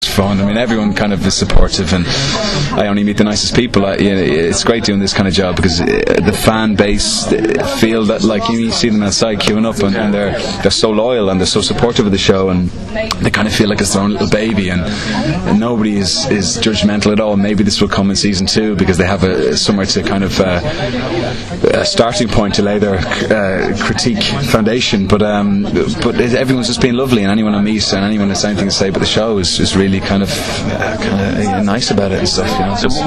Now here are a few extracts, with audio, from the interviews with Aidan and Lenora, who is also currently starring as fashion designer Ali in BBC1 drama series Material Girl: